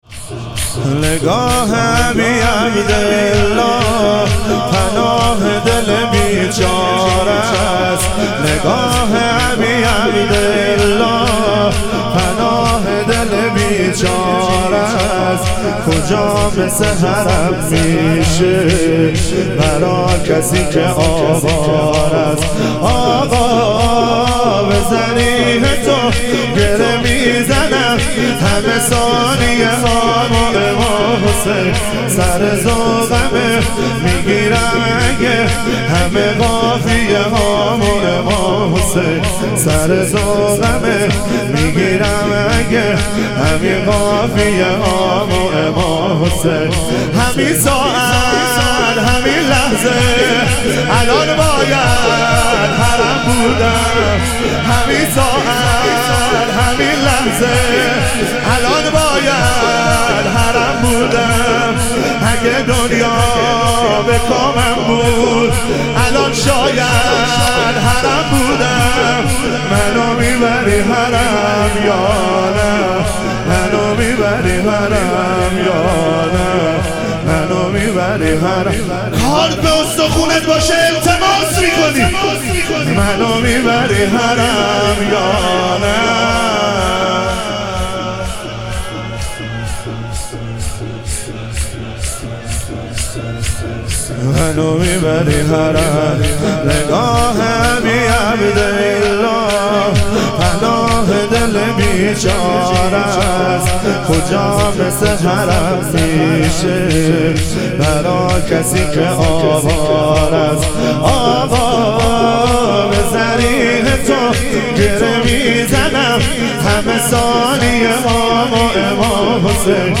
اربعین امام حسین علیه السلام - شور